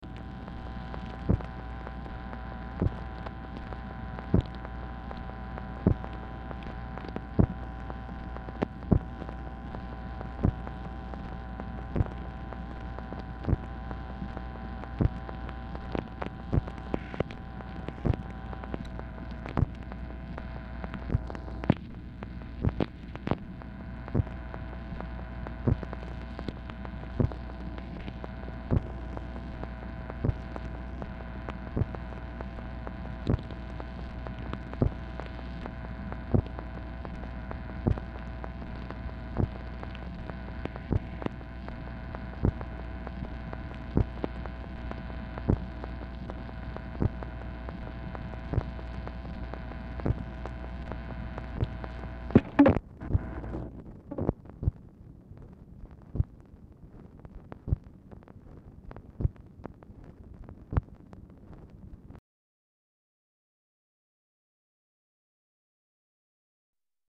Format Dictation belt
White House Telephone Recordings and Transcripts Speaker 2 MACHINE NOISE Specific Item Type Telephone conversation